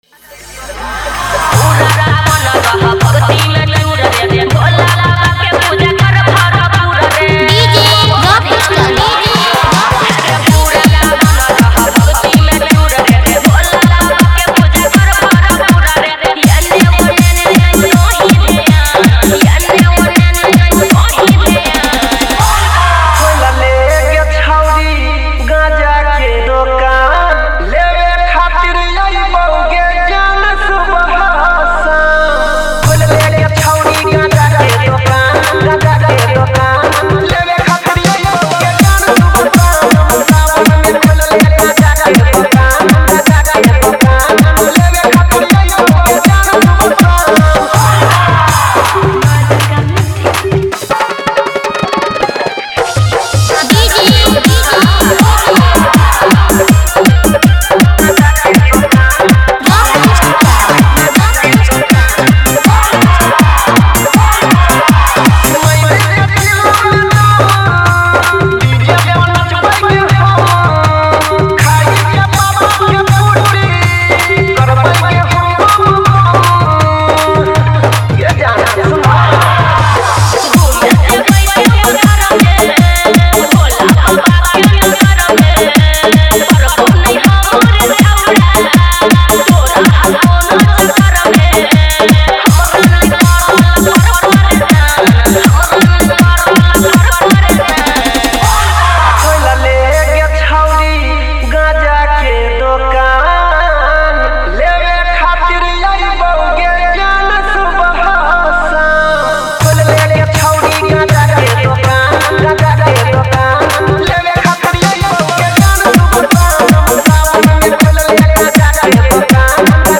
Category:  Bol Bam 2021 Dj Remix Songs